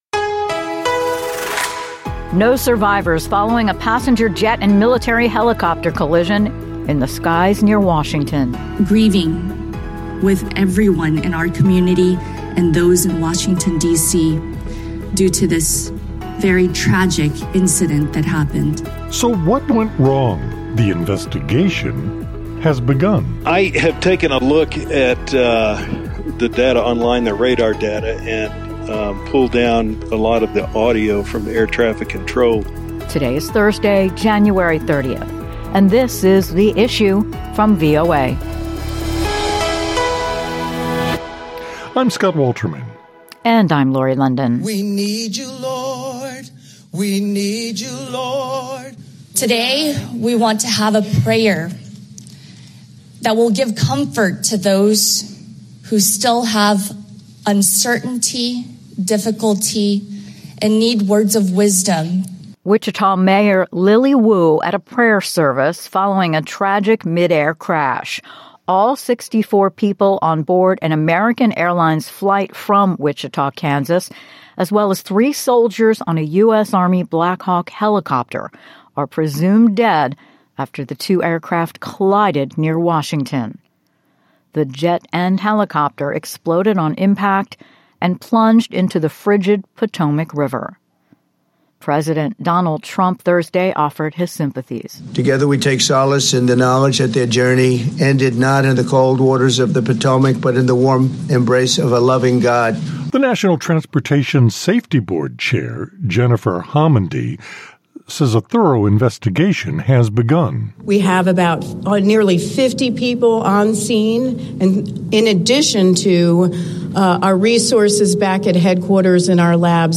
VOA-small.mp3